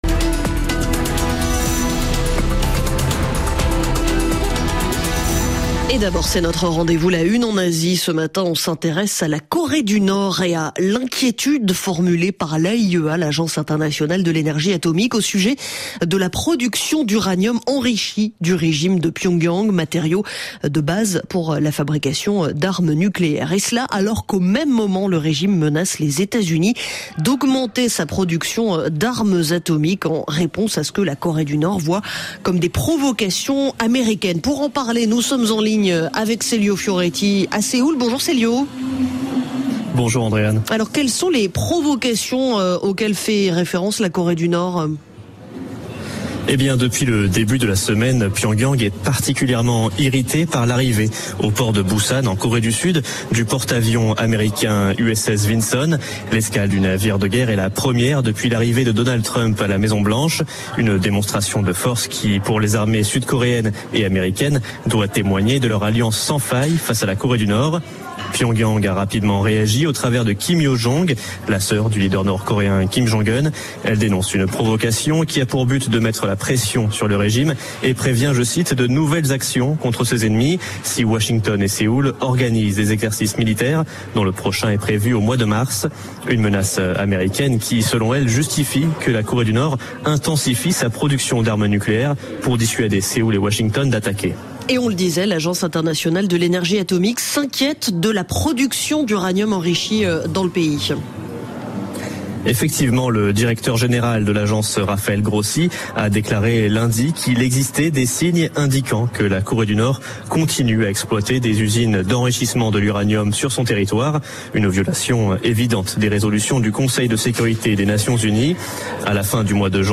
Du lundi au vendredi, retrouvez ce qui fait la Une de l'actualité en Asie aujourd'hui, avec les journalistes du service international et les correspondants de RFI ainsi que nos correspondants sur le continent.